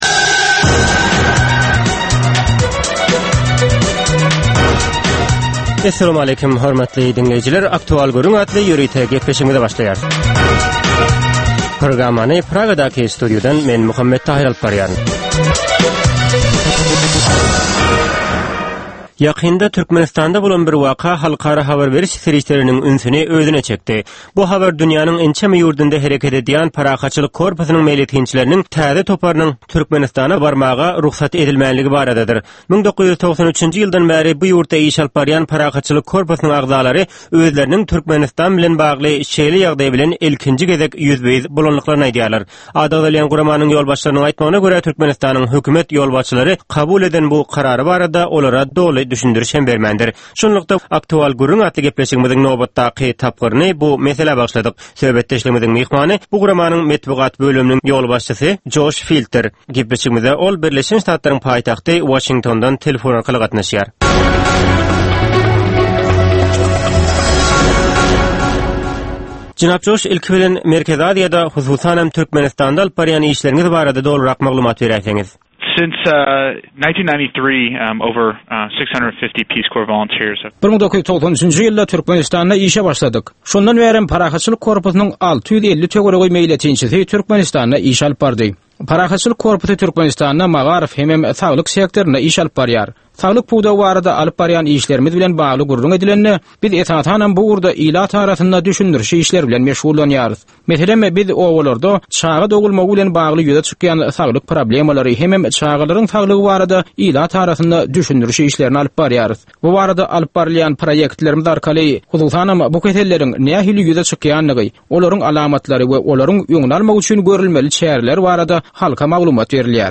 Hepdäniň dowamynda Türkmenistanda ýa-da halkara arenasynda ýüze çykan, bolup geçen möhüm wakalar, meseleler barada anyk bir bilermen ýa-da synçy bilen geçirilýän 10 minutlyk ýörite söhbetdeşlik. Bu söhbetdeşlikde anyk bir waka ýa-da mesele barada synçy ýa-da bilermen bilen aktual gürründeşlik geçirilýär we meseläniň dürli ugurlary barada pikir alyşylýar.